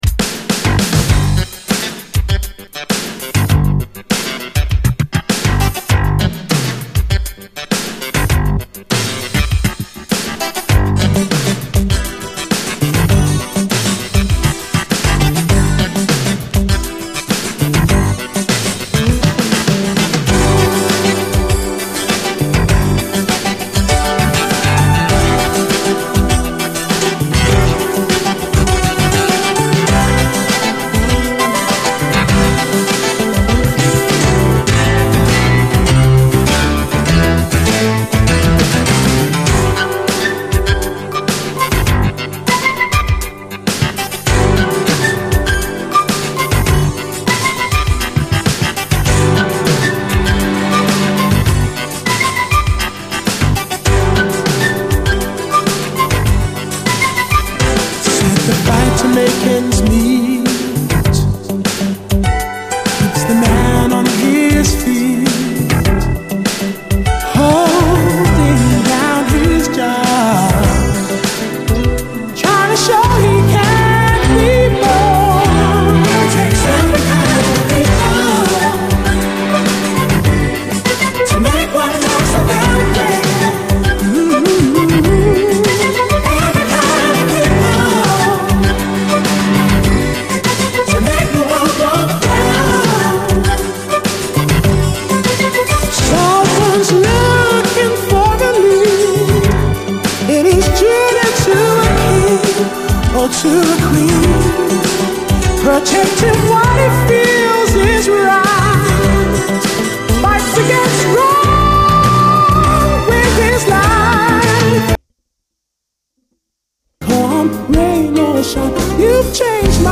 80’S UKディスコ・ラップ〜オールドスクール・ラップ
スウィートで可愛らしいメロディーのダブ・トラック